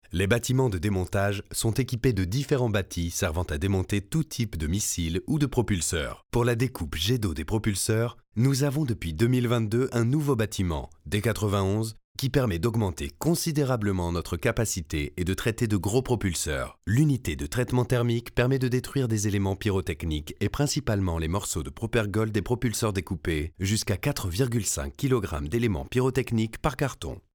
Voix off
56532 - voix off casting
20 - 60 ans